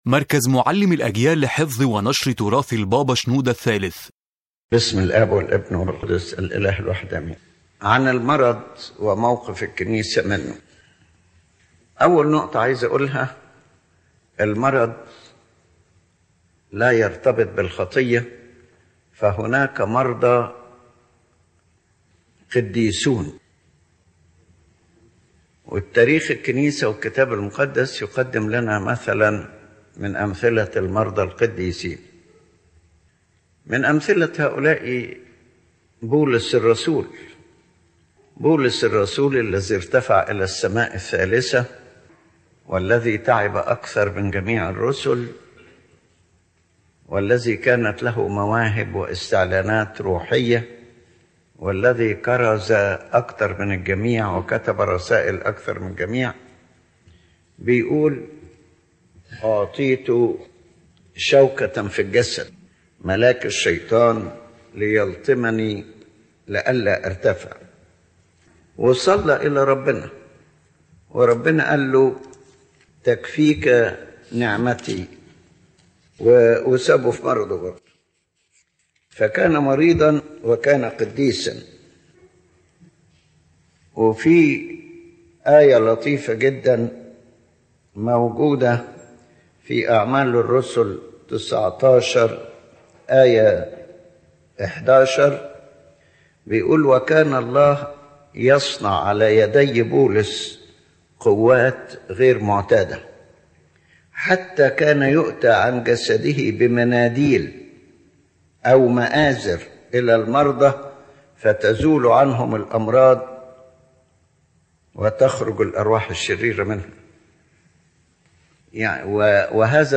The General Message of the Lecture